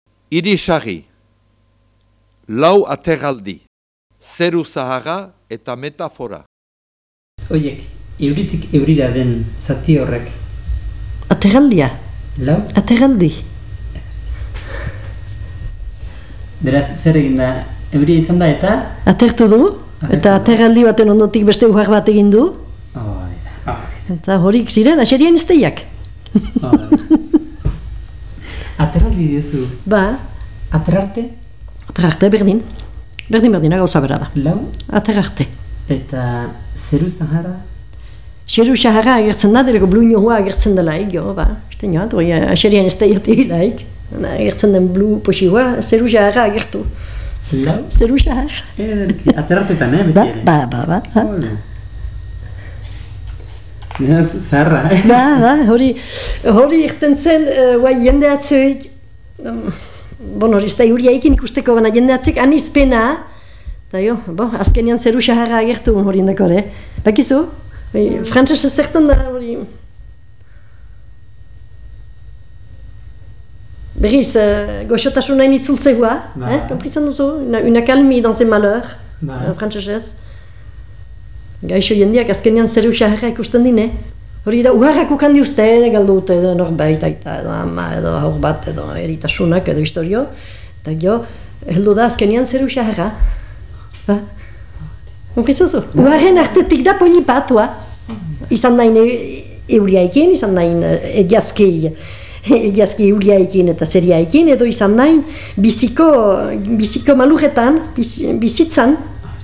Aterraldia eta zeru-zaharra zer diren adierazten du lekukoak.